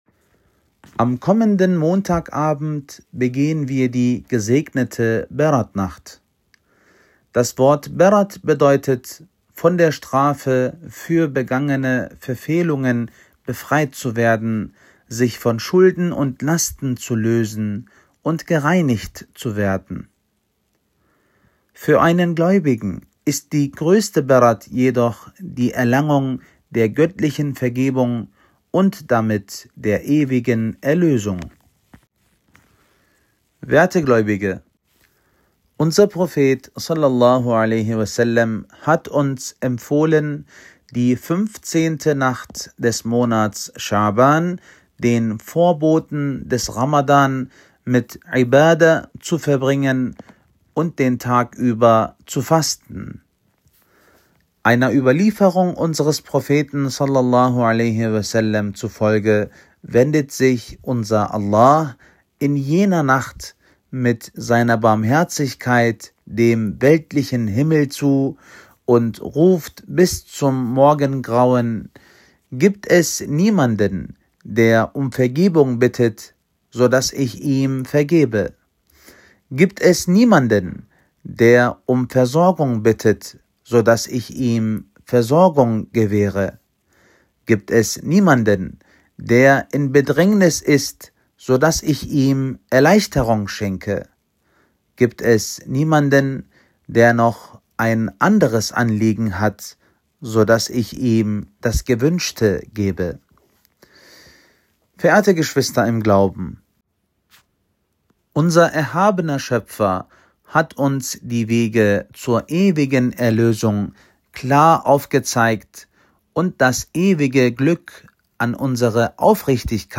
Freitagspredigt